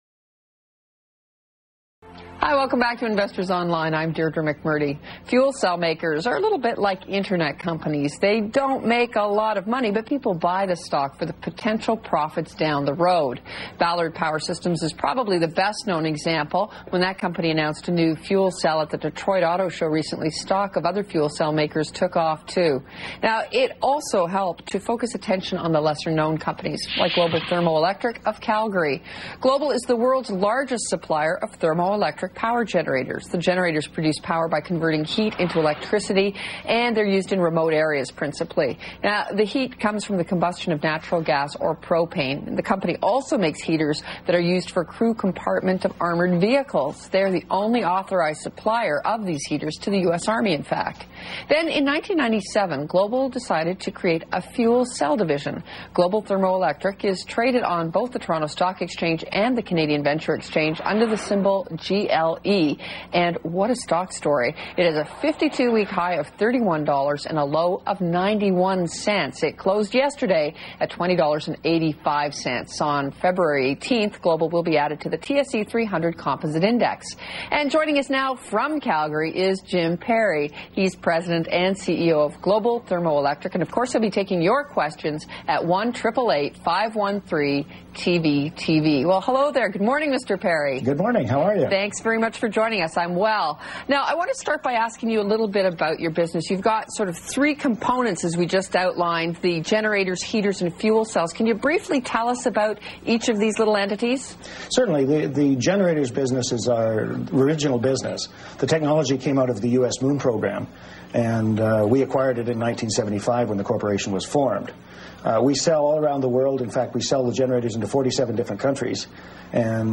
Jan. 28/00: Sound file from the Investors on-line interview